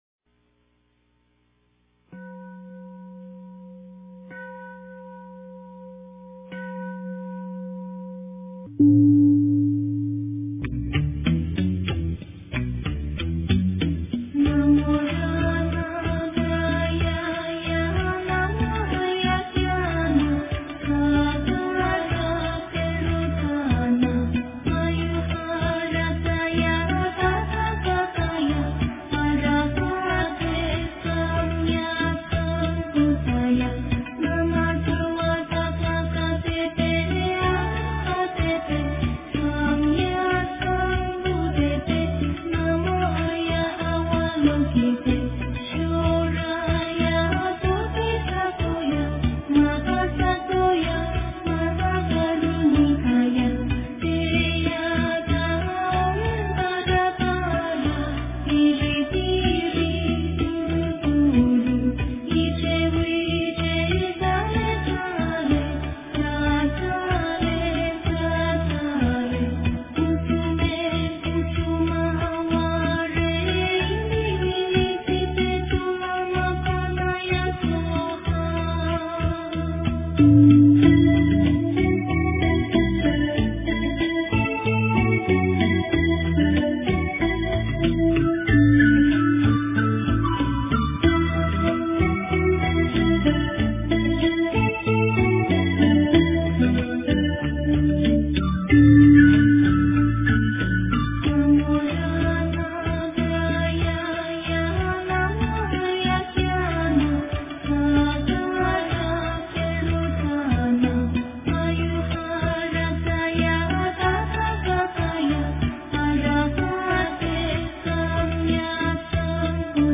大悲咒 诵经 大悲咒--佚名 点我： 标签: 佛音 诵经 佛教音乐 返回列表 上一篇： 心经 下一篇： 大悲咒 相关文章 貧僧有話26說：我的恩怨情仇--释星云 貧僧有話26說：我的恩怨情仇--释星云...